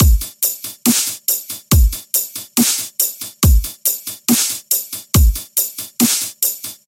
140 Dubstep Drums 1 DRY
描述：简单的2x4 Dubstep鼓循环，没有应用任何效果。140 BPM，用FL Studio制作